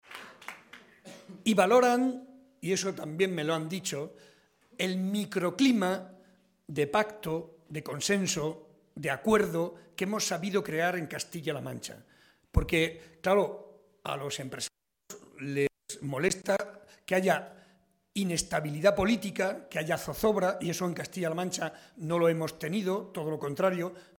Audio Barreda mitin Illescas